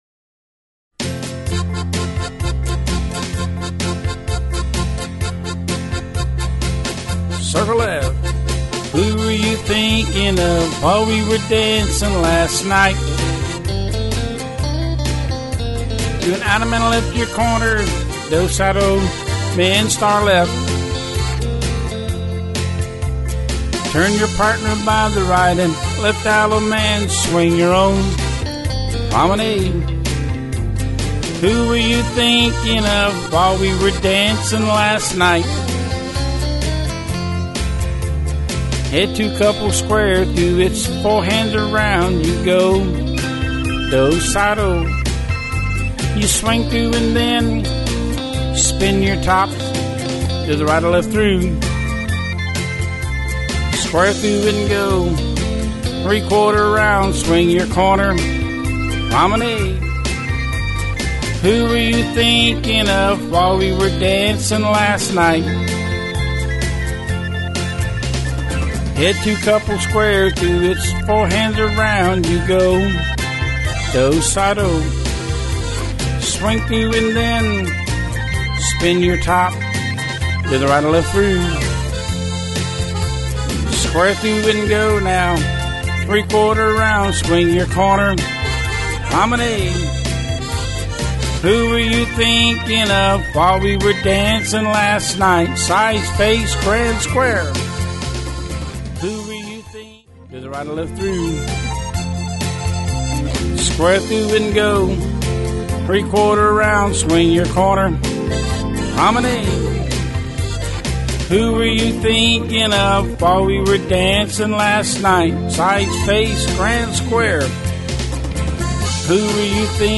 Singing Call